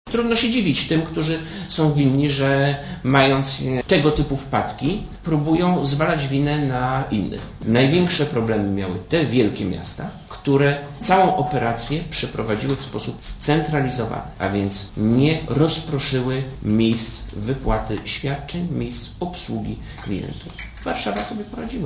Wiceminister gospodarki i pracy Krzysztof Pater jednoznacznie wskazuje na winę samorządów.
* Mówi Krzysztof Pater*